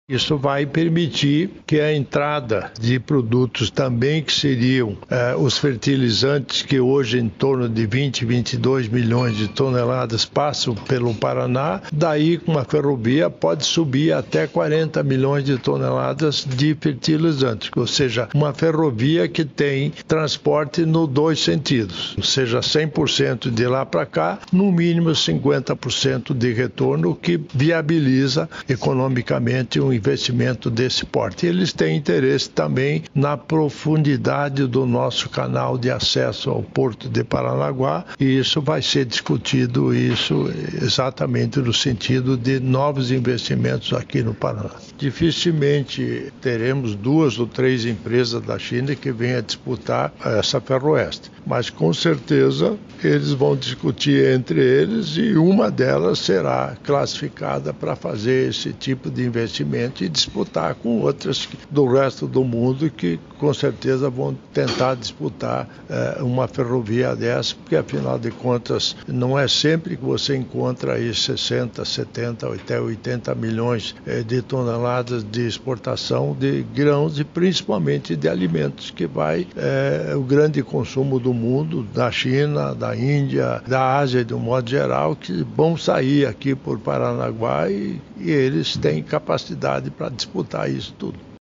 Sonora do governador em exercício Darci Piana sobre a reunião com o grupo chinês que controla controla o Terminal de Contêineres de Paranaguá